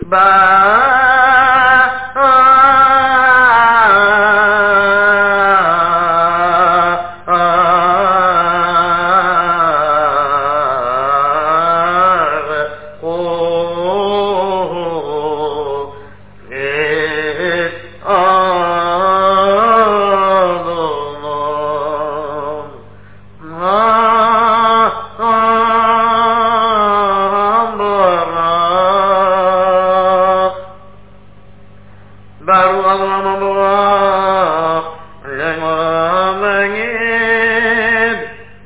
Semuchim join the chazzan